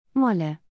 (Please note: the audio pronunciations in this post are in a “Standard German” accent, so they don’t sound exactly as they would coming from a real Berliner.)